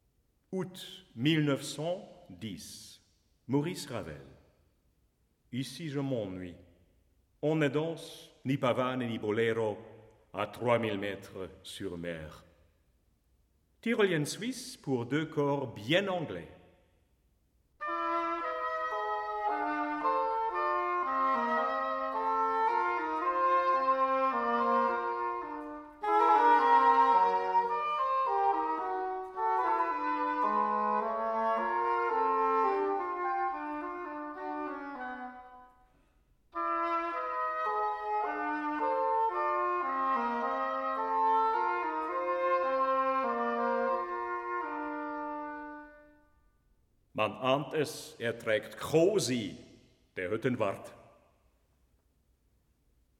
Albert Moeschinger: Maurice Ravel, 1910 (2 english horns)